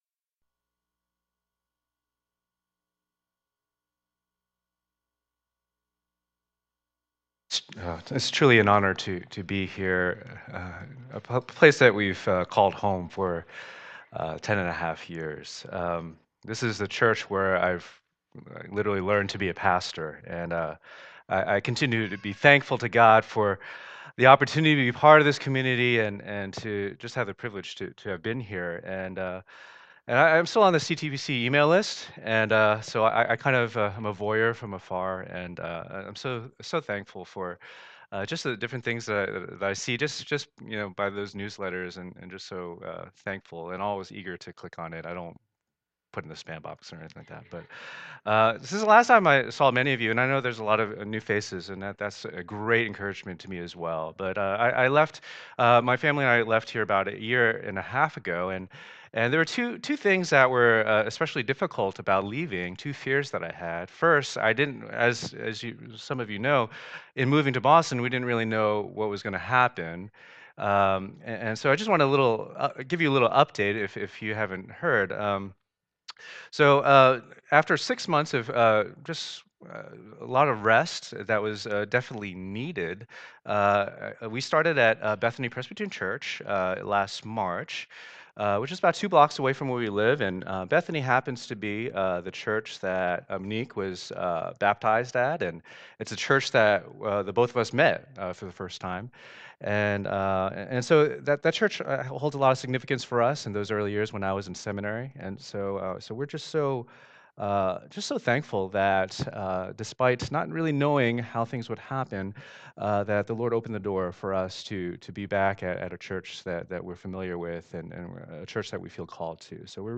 Luke 10:38-42 Service Type: Lord's Day %todo_render% « Good News for the Faithful Money